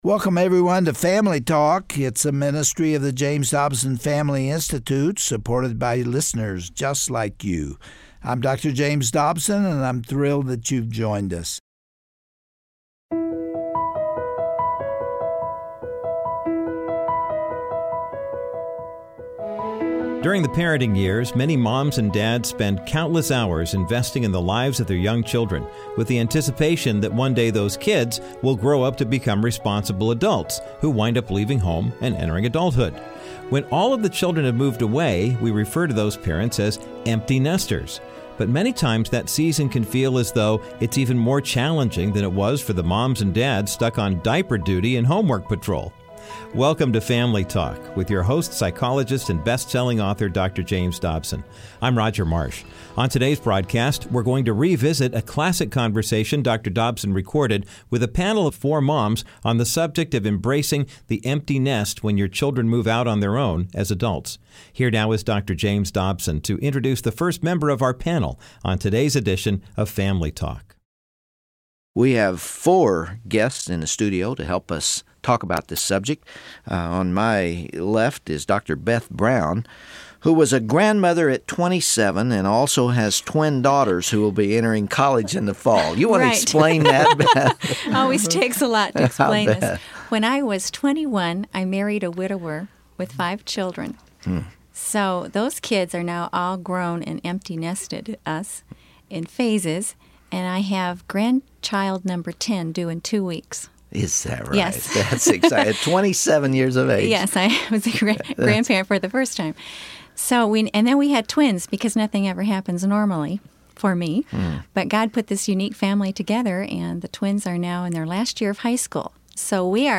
But now, just as they are leaving for college and moving out of the house, parents are entering into a new stage of life as well. On today’s classic edition of Family Talk, Dr. James Dobson begins a four-part series, joined by a panel of seasoned mothers to explore the emotions, trials, and even liberties of the “empty nest.”